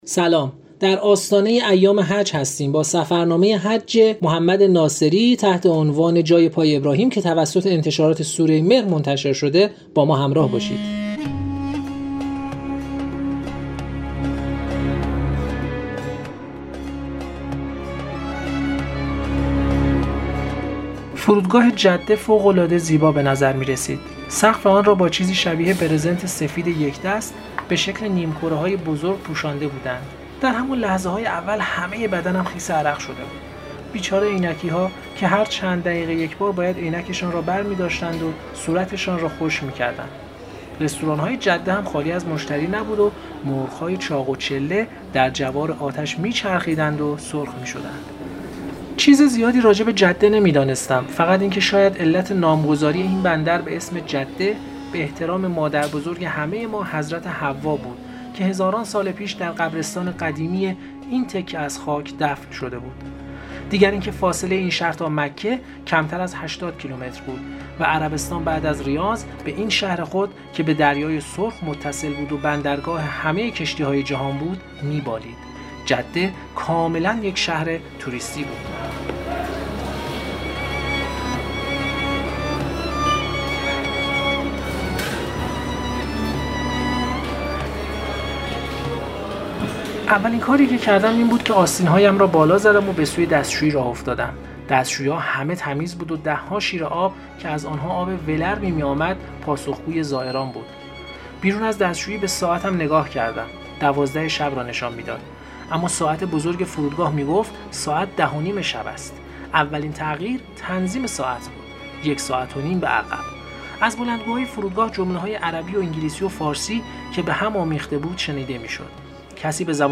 در دومین بخش از این مجموعه پادکست‌ها، ادامه بخش‌های ابتدایی کتاب «جای پای ابراهیم» که سفرنامه حج محمد ناصری در سال ۱۳۷۰ شمسی است را می‌شنویم.